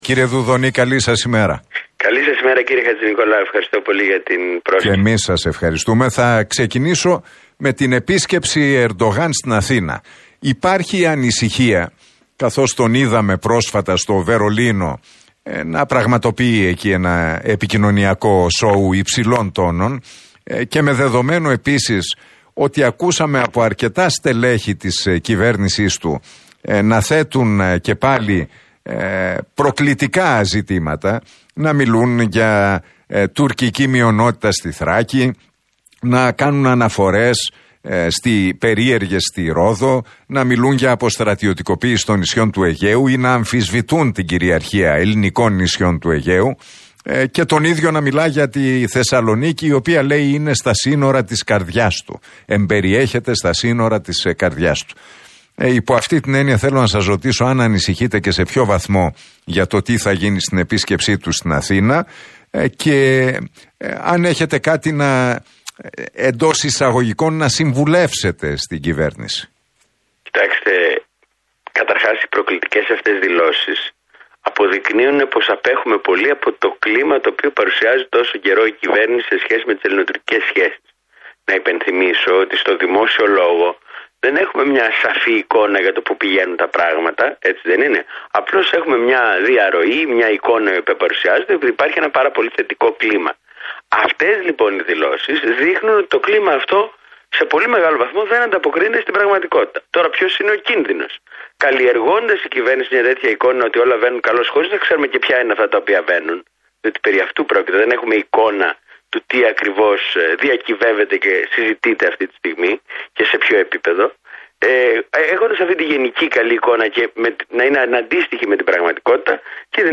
Αυτές οι δηλώσεις δείχνουν ότι το κλίμα αυτό δεν ανταποκρίνεται στην πραγματικότητα» δήλωσε ο βουλευτής του ΠΑΣΟΚ – ΚΙΝΑΛ μιλώντας στην εκπομπή του Νίκου Χατζηνικολάου στον Realfm 97,8.